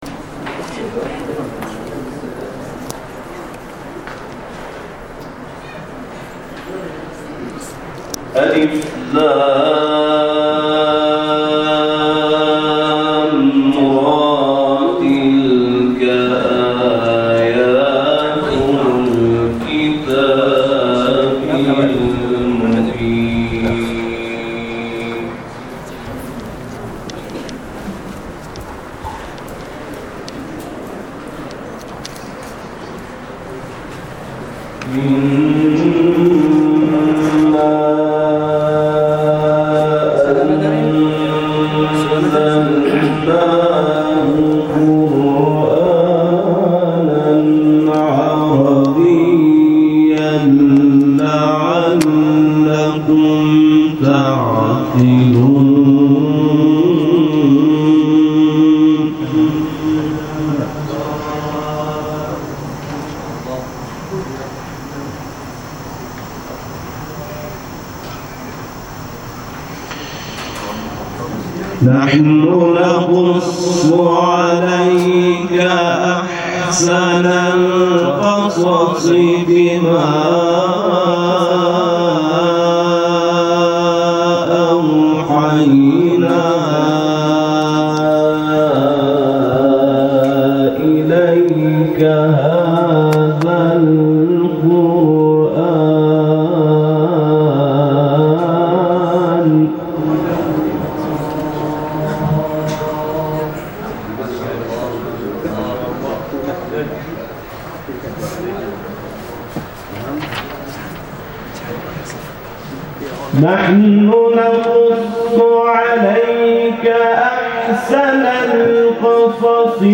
Bangaren kur’ani, sautin tilawa
a yayin da yake kira’a a gasat kur’ani ta daliban muuslmi a haramin Radawi